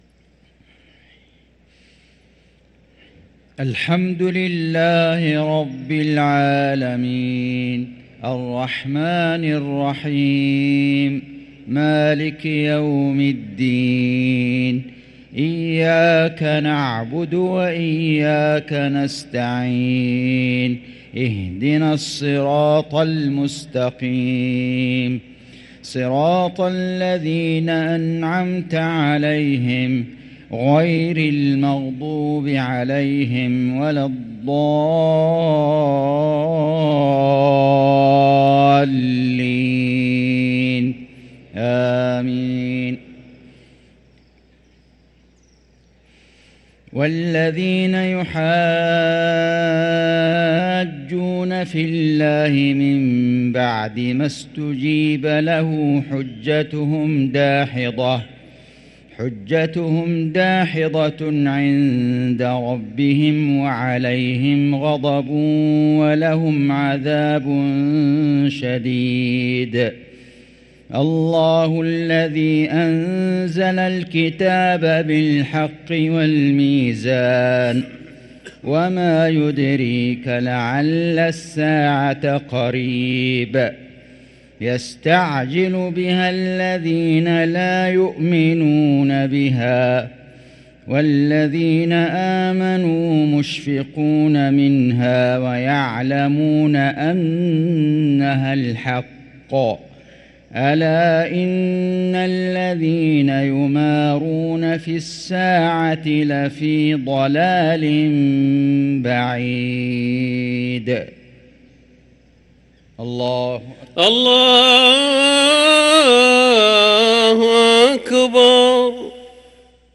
صلاة المغرب للقارئ فيصل غزاوي 16 رمضان 1444 هـ
تِلَاوَات الْحَرَمَيْن .